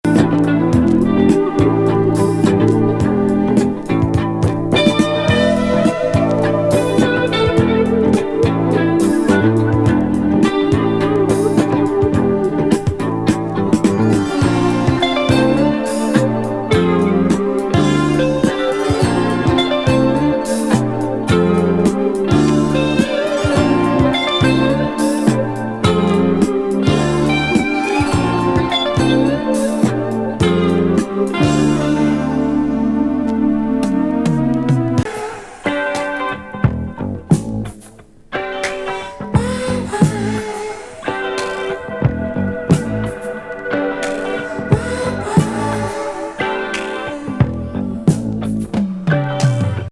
フランス産ブルーアイドソウル・アルバム79年作。レゲー風味
グルーヴィAOR